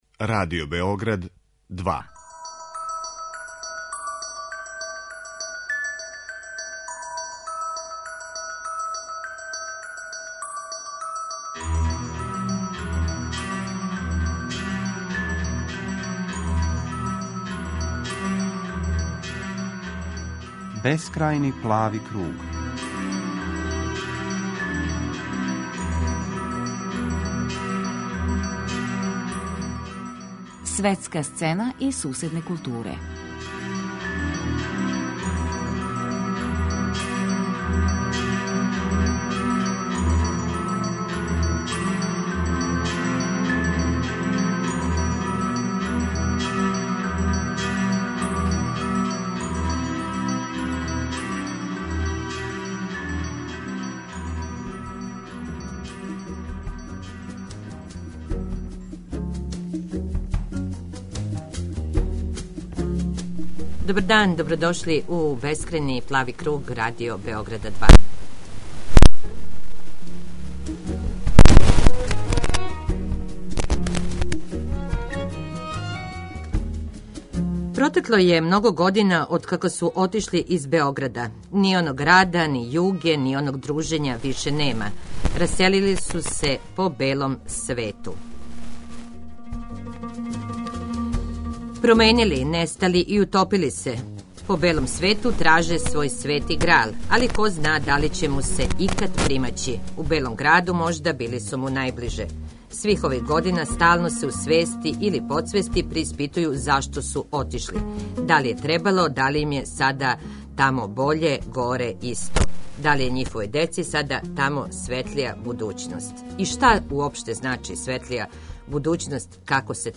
Гост емисије